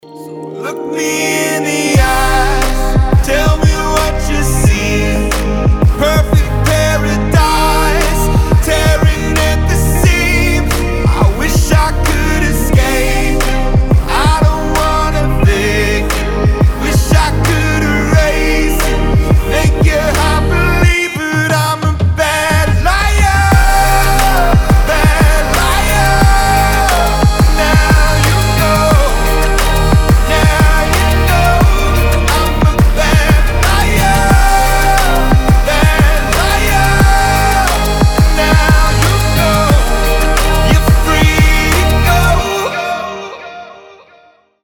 • Качество: 320, Stereo
мелодичные
Pop Rock
alternative
indie rock